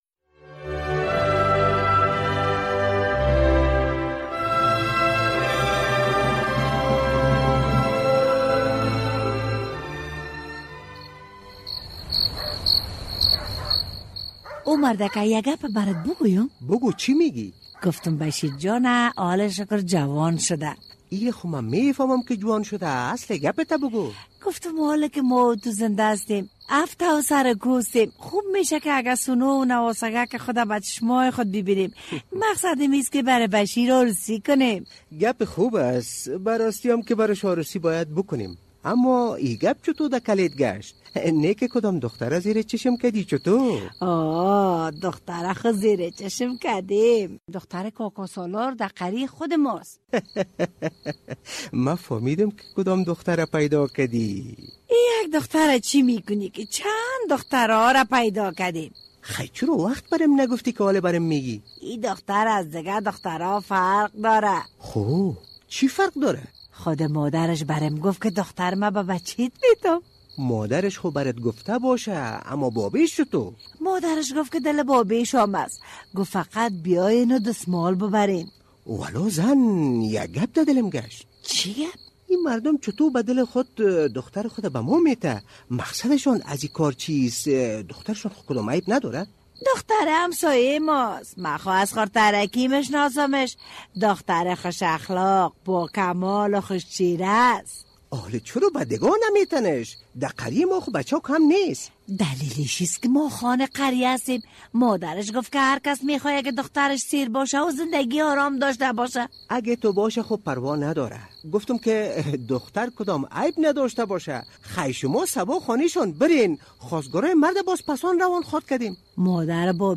این را در درامه می‌شنویم